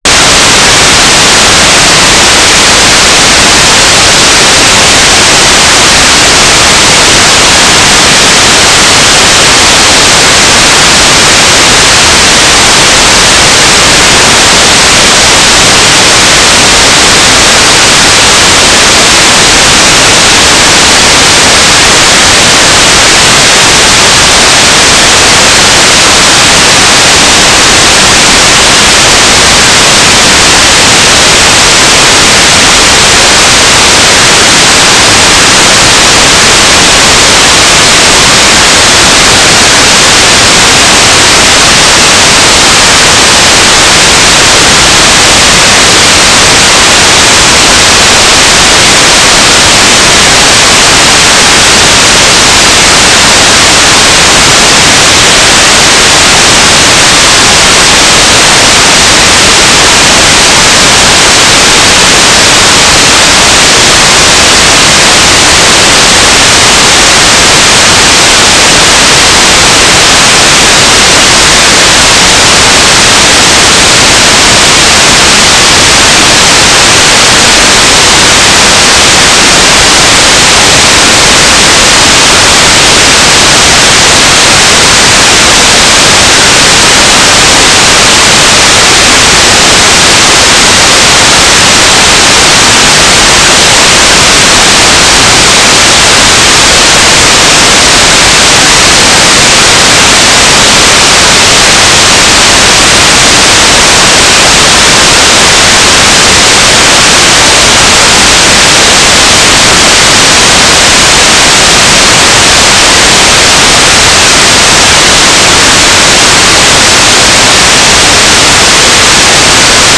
"transmitter_description": "Mode U - FSK9k6 - Transmitter",